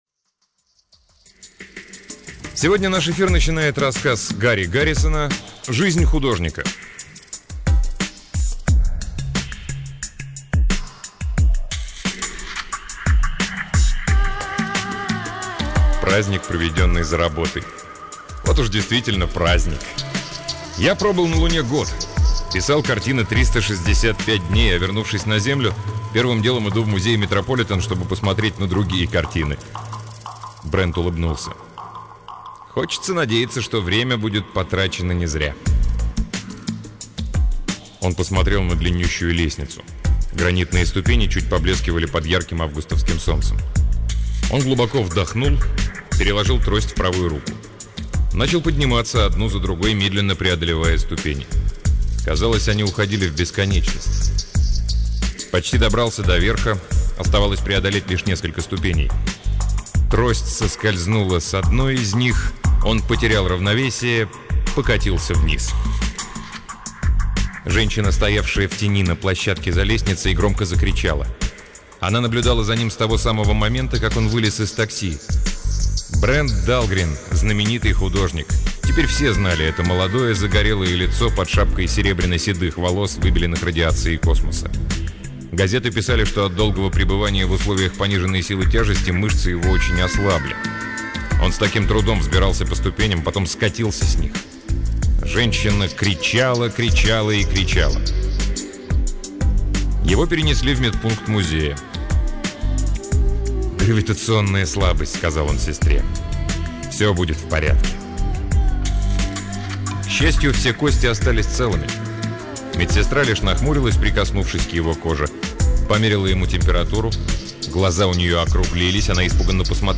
Аудиокнига Гарри Гаррисон — Жизнь Художника